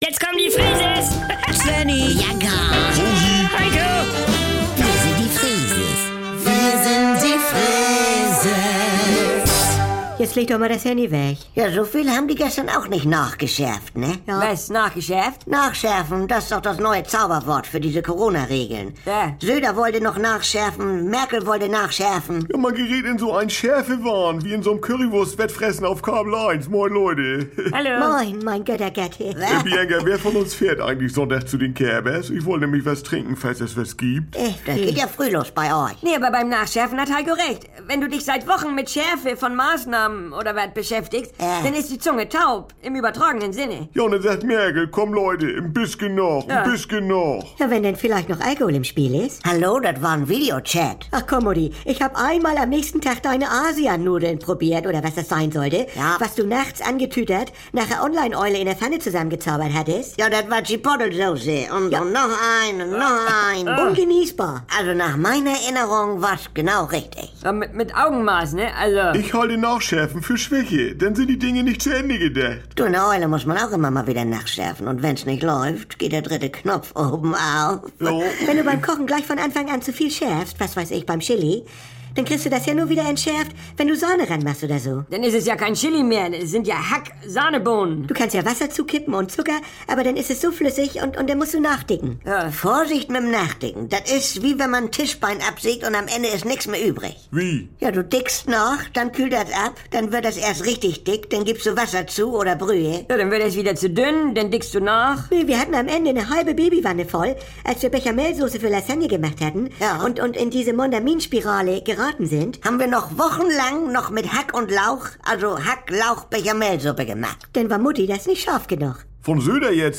Hier gibt's täglich die aktuelle Freeses-Folge, direkt aus dem Mehrgenerationen-Haushalt der Familie Freese mit der lasziv-zupackenden Oma Rosi, Helikopter-Mama Bianca, dem inselbegabten Svenni sowie Untermieter und Labertasche Heiko. Alltagsbewältigung rustikal-norddeutsch...